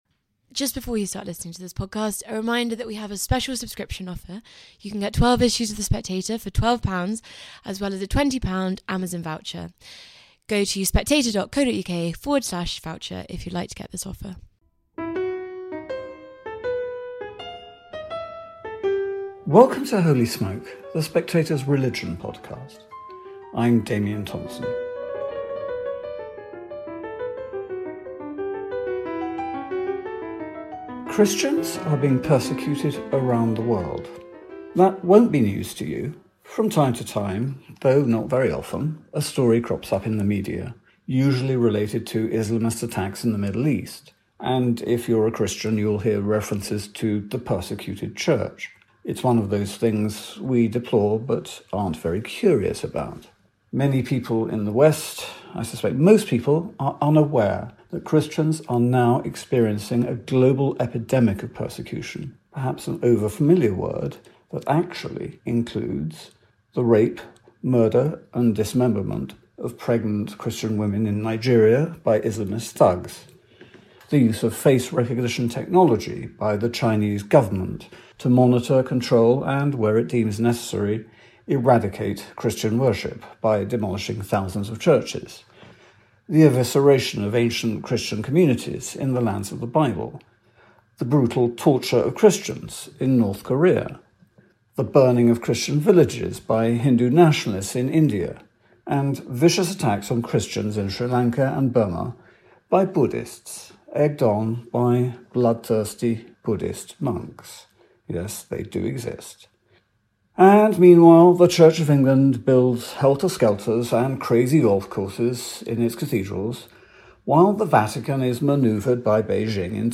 News Commentary, News, Daily News, Society & Culture